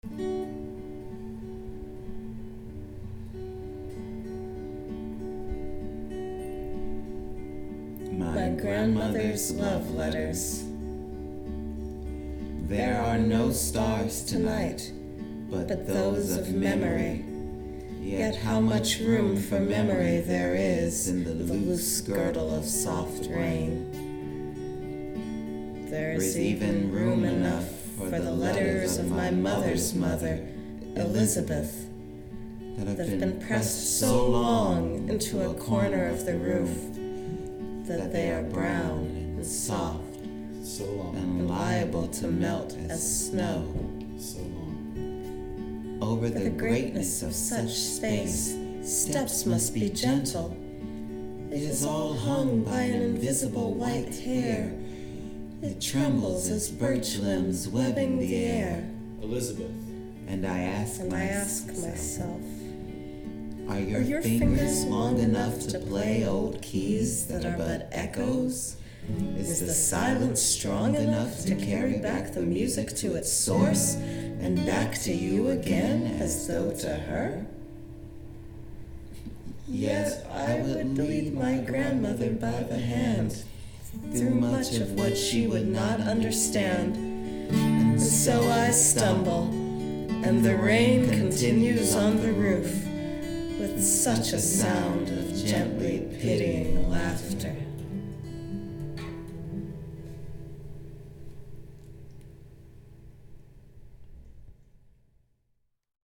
Recorded at the Baltimore Free School on Saturday, October 2.
guitar
Discovery that “My Grandmother’s Love Letters” is the first text we’ve approached that we all want to do in unison, all the way through.